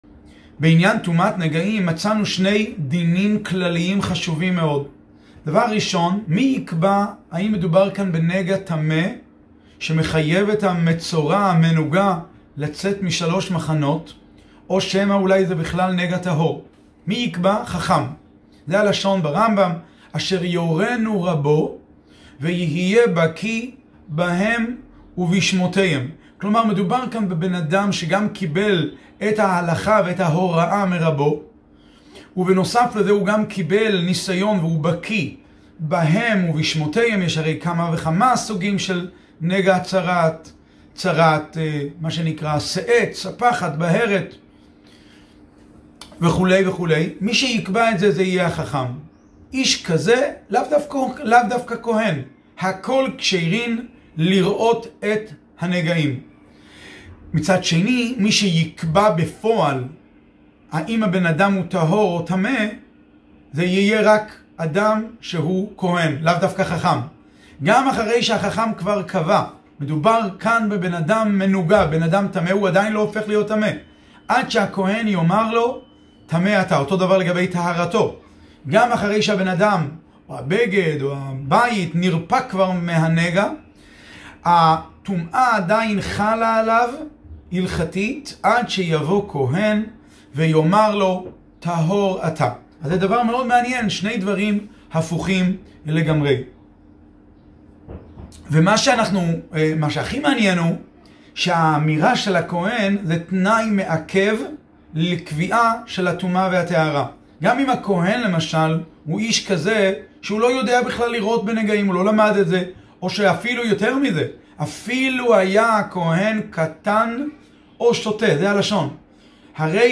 לימוד